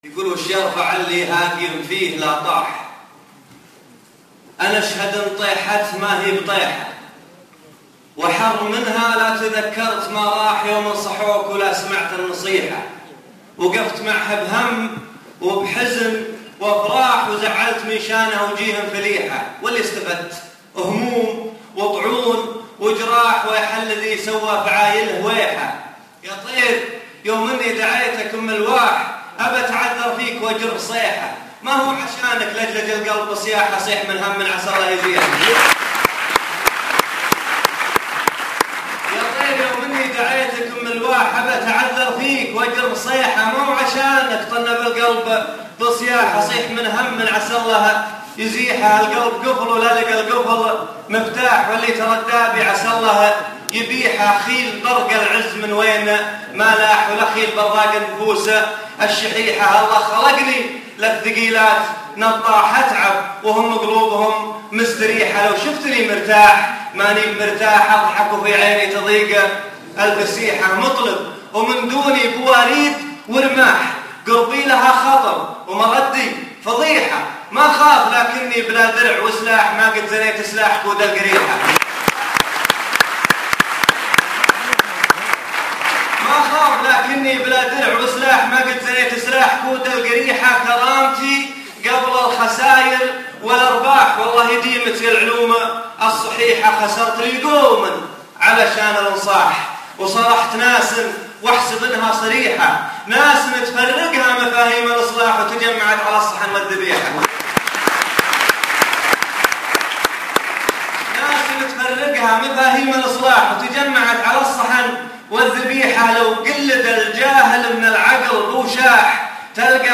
ياطير [ امسيه كتارا ]